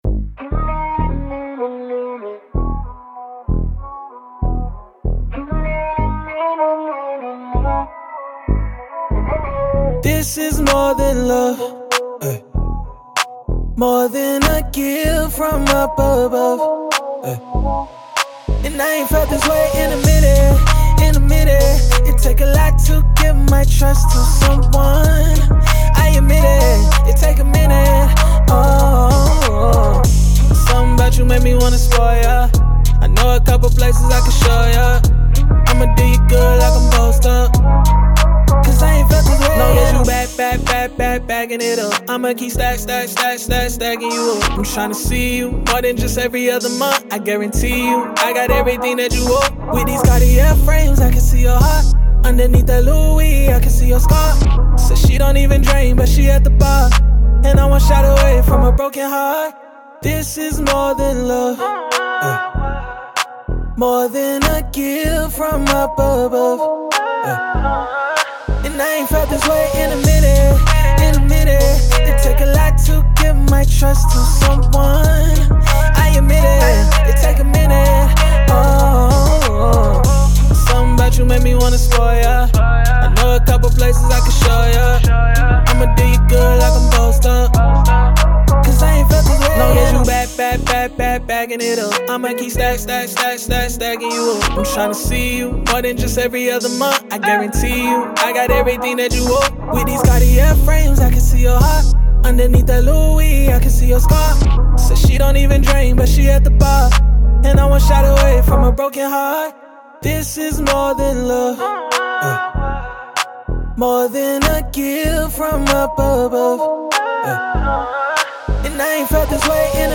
R&B, Hip Hop, Pop, Dance
G# Min